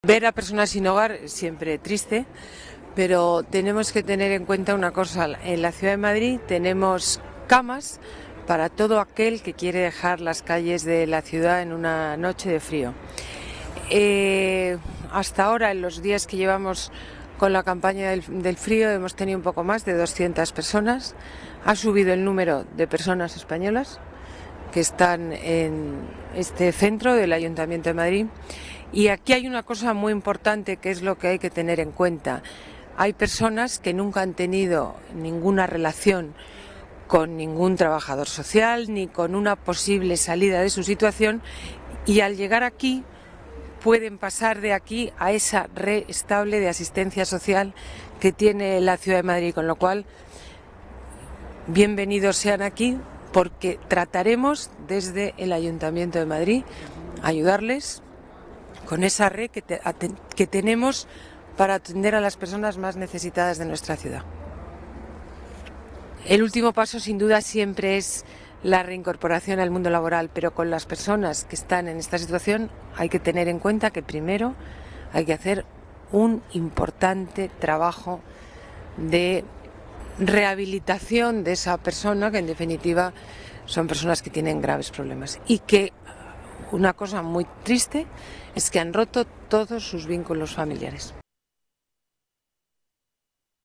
Nueva ventana:Declaraciones Botella Centro Acogida Pinar de San José